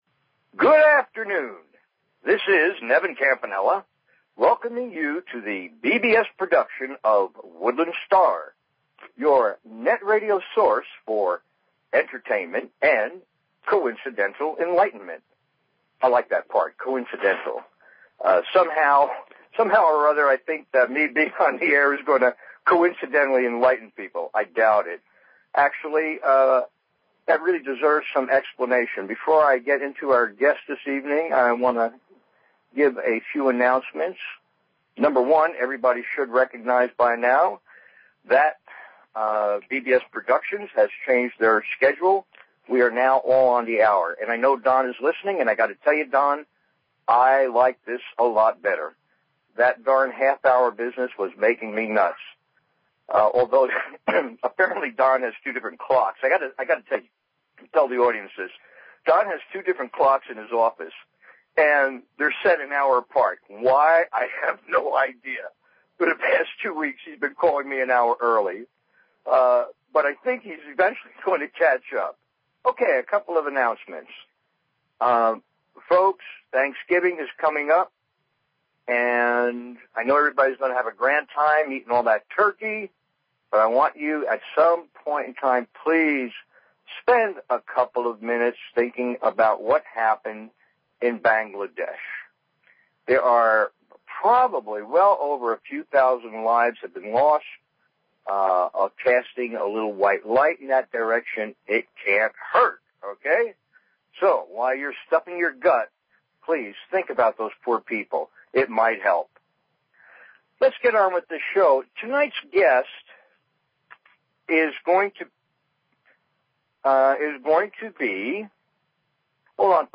Talk Show Episode, Audio Podcast, Woodland_Stars_Radio and Courtesy of BBS Radio on , show guests , about , categorized as